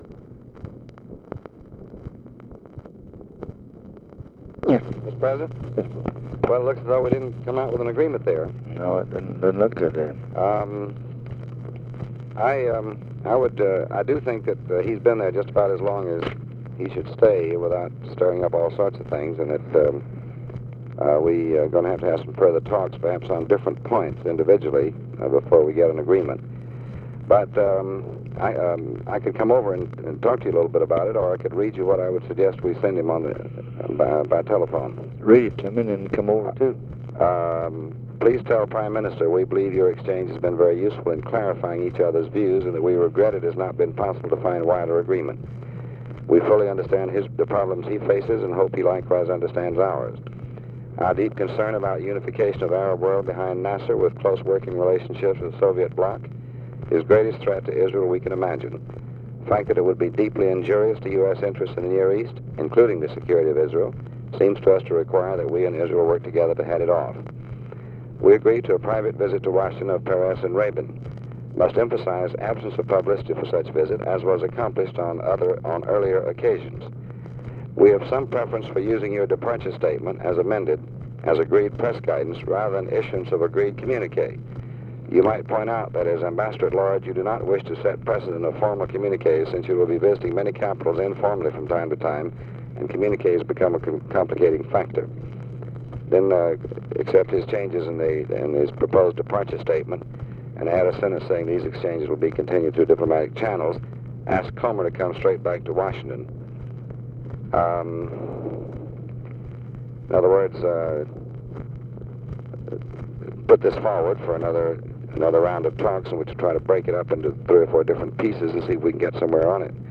Conversation with DEAN RUSK, February 28, 1965
Secret White House Tapes